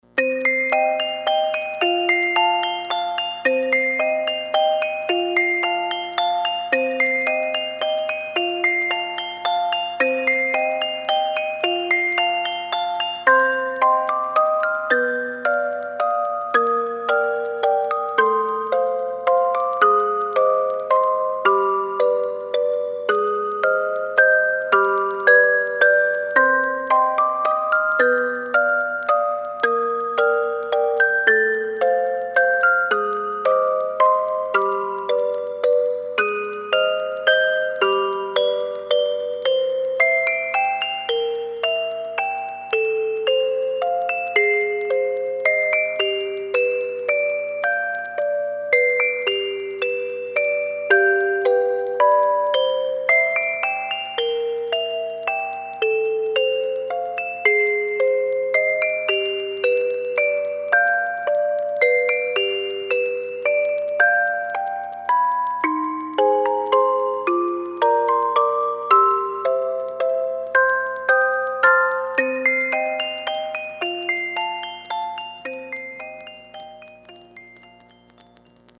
古いオルゴールが奏でる切ない旋律。回想シーン、ホラーゲームのタイトルやエンディングに使えそう